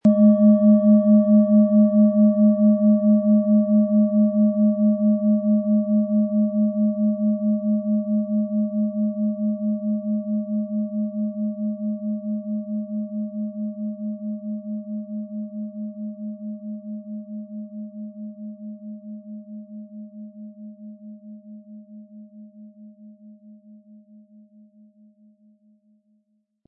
Planetenschale® Visionen und übersinnliche Begabungen & Entdecke Deine Selbsttäuschungen mit Neptun, Ø 14,8 cm, 400-500 Gramm inkl. Klöppel
Um den Original-Klang genau dieser Schale zu hören, lassen Sie bitte den hinterlegten Sound abspielen.
MaterialBronze